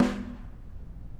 Snare2-HitSN_v1_rr2_Sum.wav